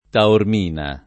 Taormina [ taorm & na ]